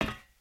metal_plate1.ogg